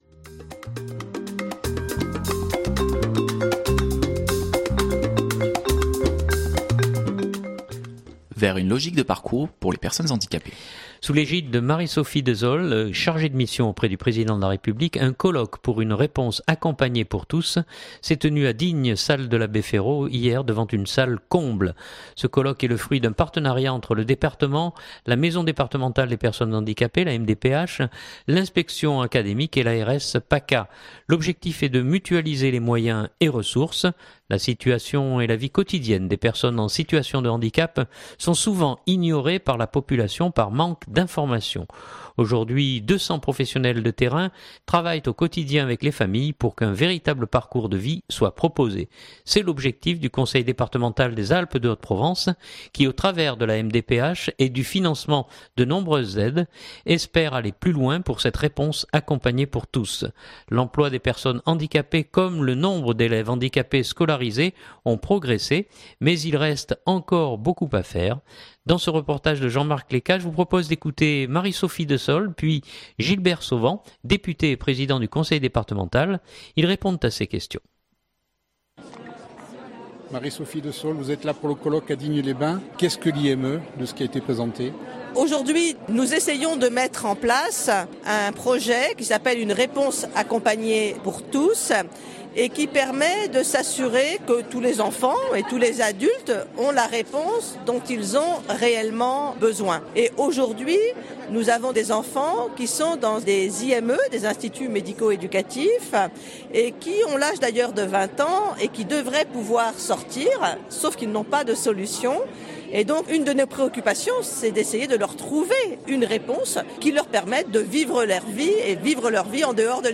Sous l’égide de Marie–Sophie Desaulle (chargée de mission auprès du président de la République) un colloque pour une réponse accompagnée pour tous s’est tenu à Digne salle de l’Abbé Ferraud devant une salle comble.